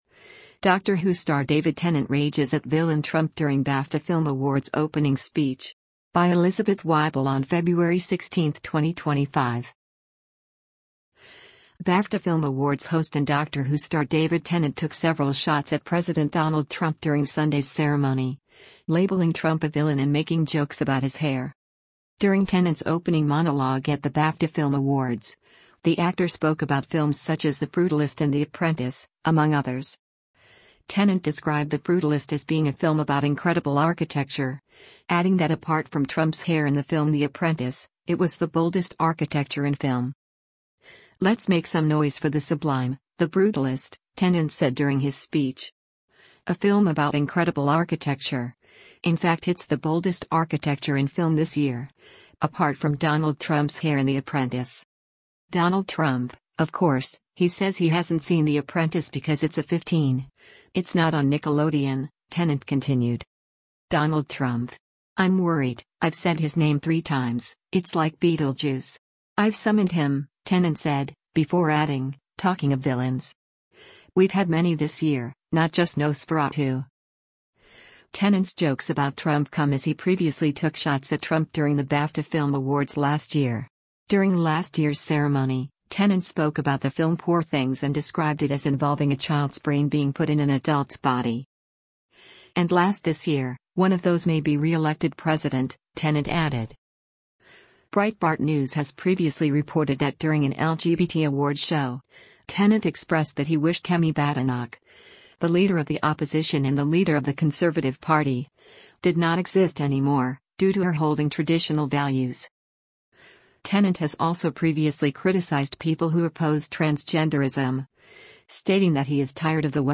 “Let’s make some noise for the sublime, The Brutalist,” Tennant said during his speech.